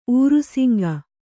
♪ ūrusiŋga